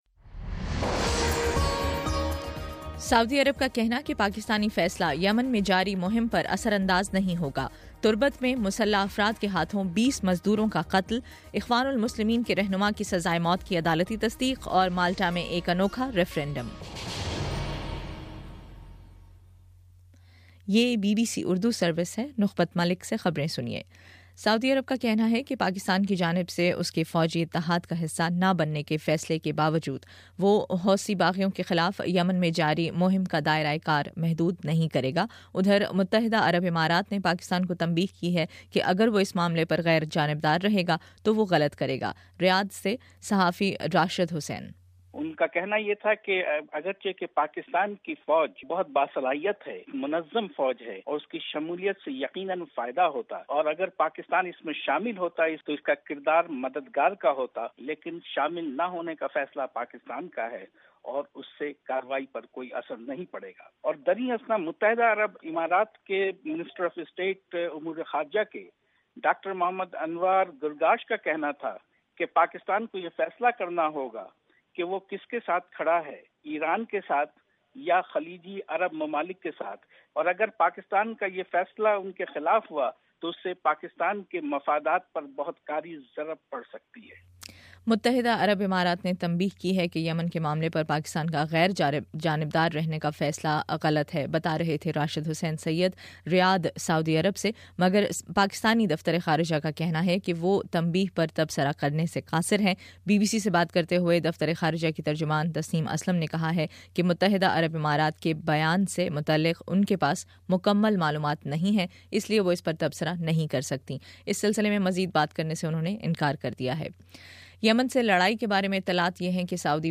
اپریل 11: شام پانچ بجے کا نیوز بُلیٹن